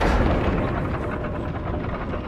Vat of Lava Rising from the Floor.wav